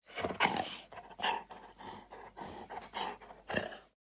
Minecraft Version Minecraft Version 1.21.5 Latest Release | Latest Snapshot 1.21.5 / assets / minecraft / sounds / mob / wolf / angry / panting.ogg Compare With Compare With Latest Release | Latest Snapshot
panting.ogg